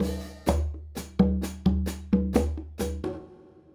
Conga and Hi Hat 04.wav